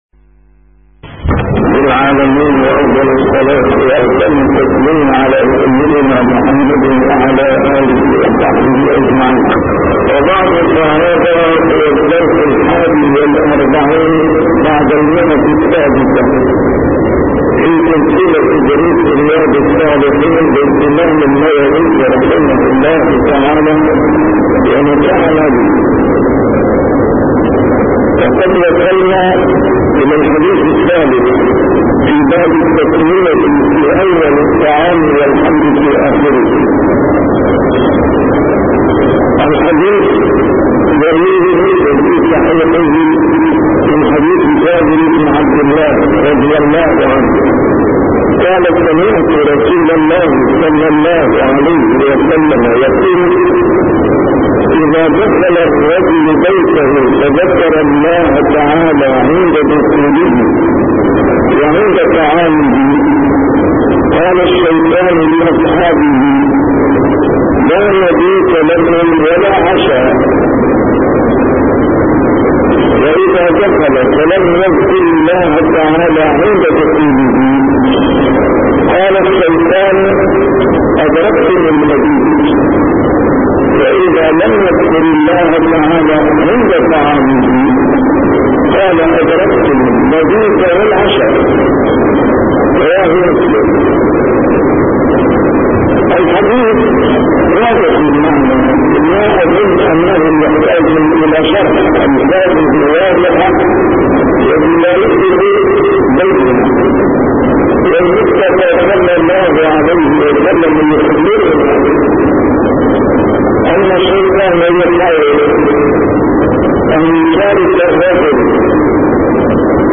A MARTYR SCHOLAR: IMAM MUHAMMAD SAEED RAMADAN AL-BOUTI - الدروس العلمية - شرح كتاب رياض الصالحين - 641- شرح رياض الصالحين: التسمية في أول الطعام والحمد في آخره